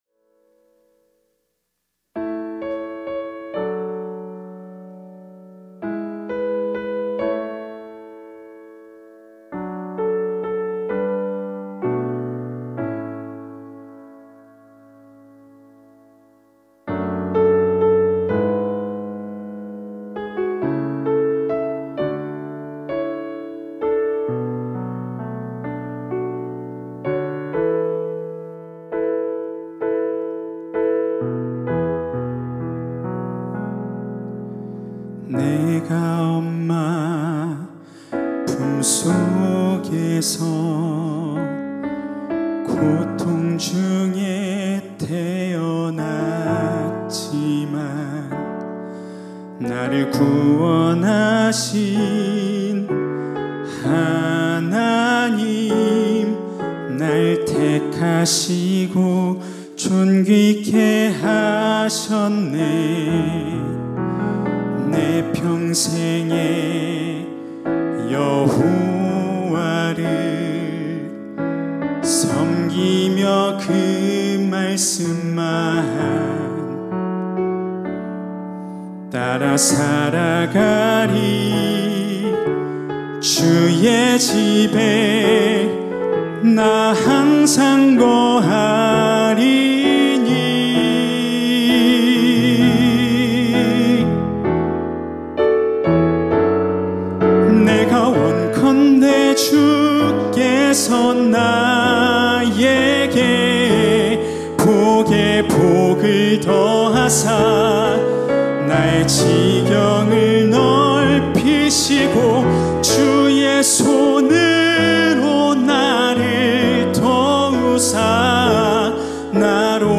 특송과 특주 - 야베스의 기도